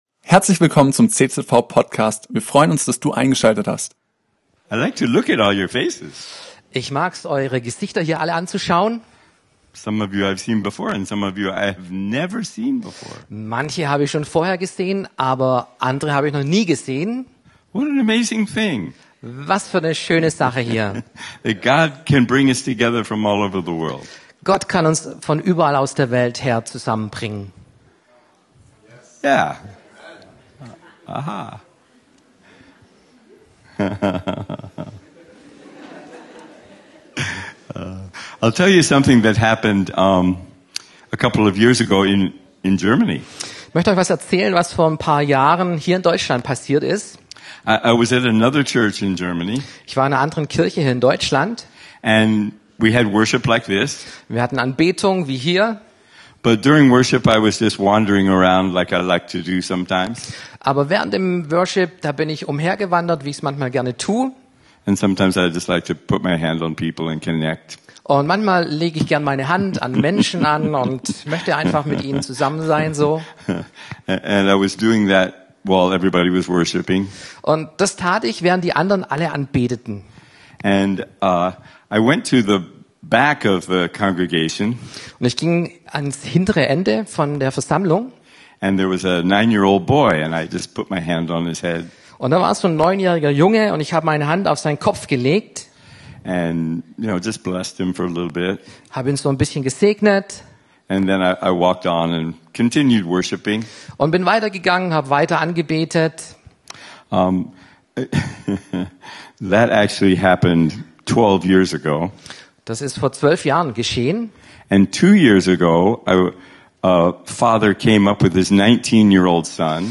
Heilungsgottesdienst ~ CZV Crailsheim Podcast